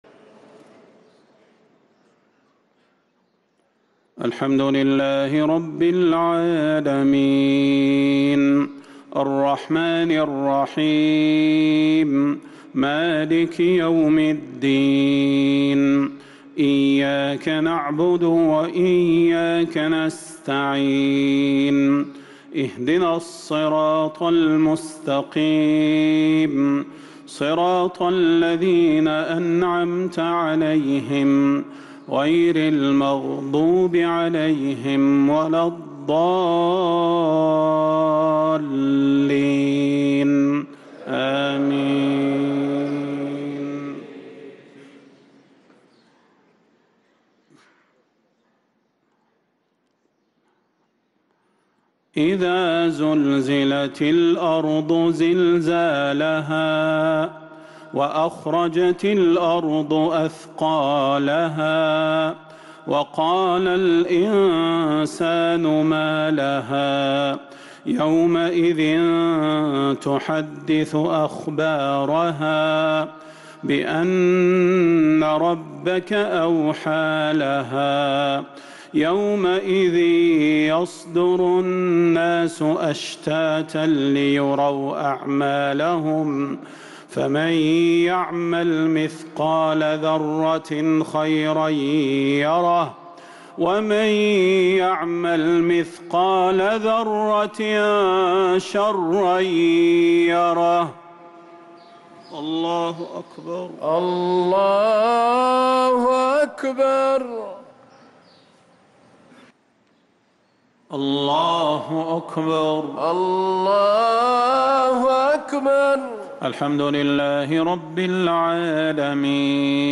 صلاة المغرب للقارئ صلاح البدير 23 ربيع الأول 1445 هـ
تِلَاوَات الْحَرَمَيْن .